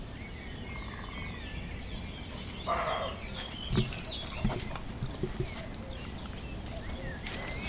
D’accordo, sul cuculo ci siamo tutti.
Non cani ma caprioli, dunque, che da queste parti abbondano.
Vi propongo un “ingrandimento” del brano precedente, questa volta sotto forma di un semplice file MP3 (non di file di lavoro AUP – mi raccomando, tenere presente la distinzione):